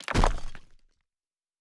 Fantasy Interface Sounds
Stone 12.wav